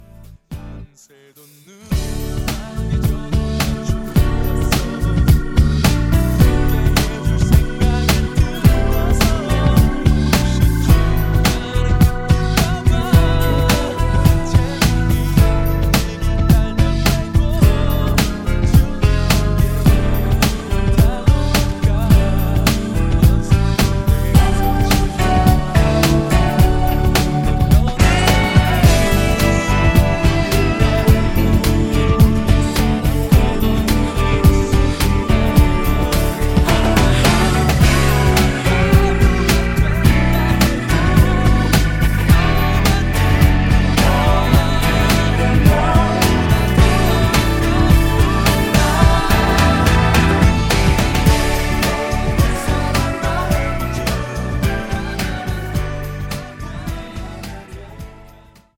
음정 -1키 4:29
장르 가요 구분 Voice MR